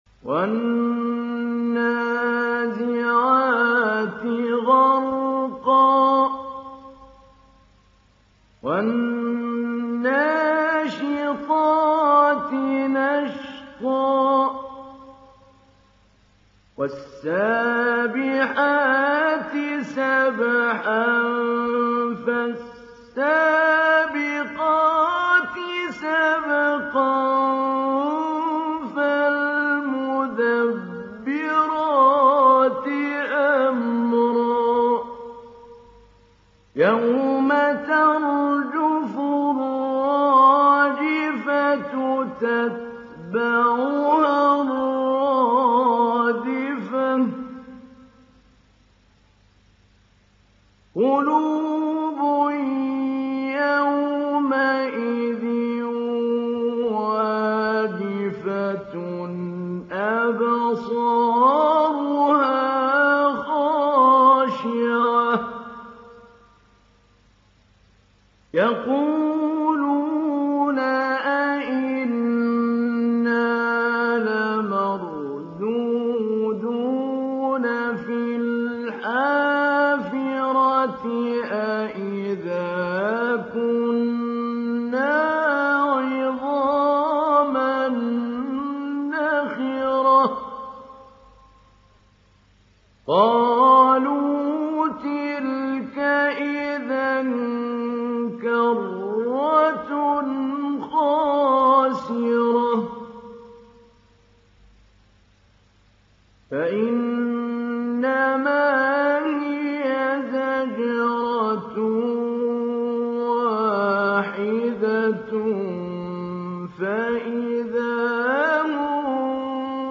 ডাউনলোড সূরা আন-নাযি‘আত Mahmoud Ali Albanna Mujawwad